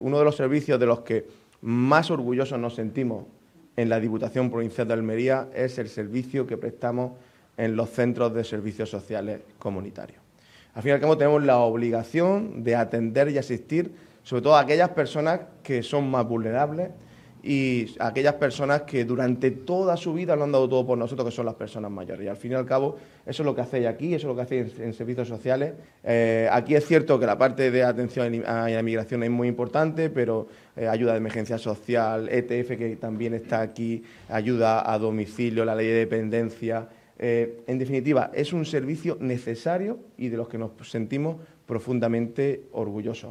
20-03_servicios_sociales_la_mojonera__presidente.mp3.mp3